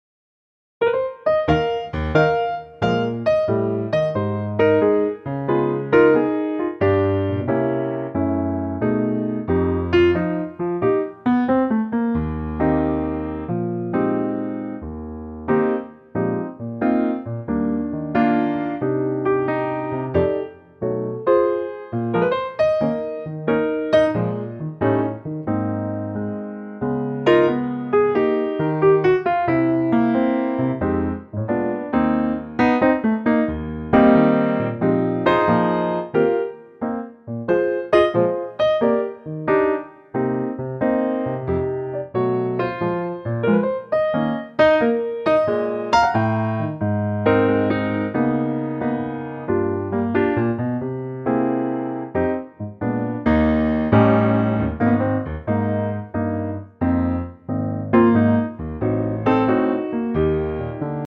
Here's a very lovely piano only arrangement.